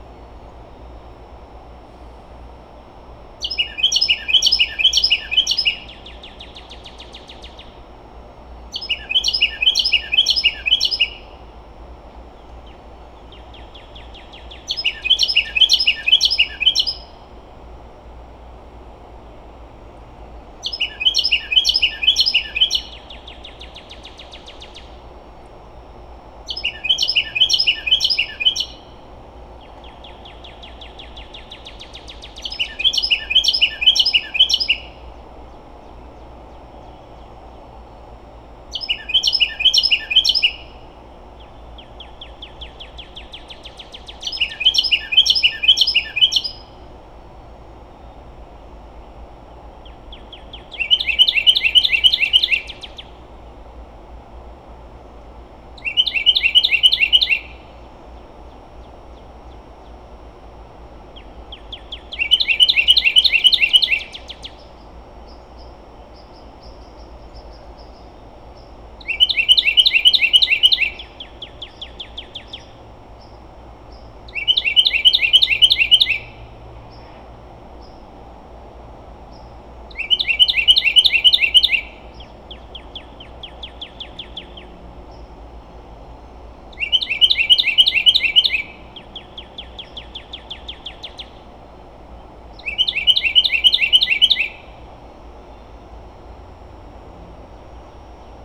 Here’s yet another Carolina Wren song.
In this 1.5-minute clip, the song changes after about 45 seconds. There, the bird breaks into a different song! Also a distant cardinal sings during the pauses as if it were duetting with the wren.
carolina-wren-32121.wav